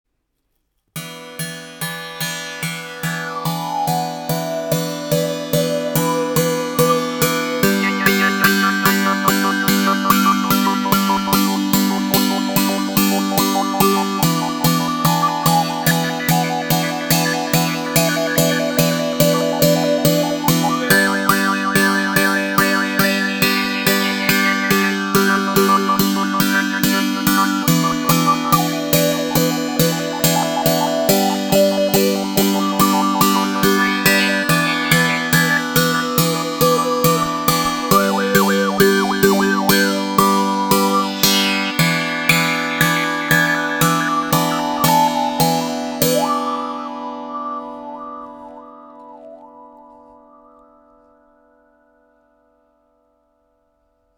Le son est très chaud et attrayant…entrainant.
Les sons proposés ici sont réalisés sans effet.
La baguette sera utilisée pour percuter les cordes, l’une, l’autre ou toutes.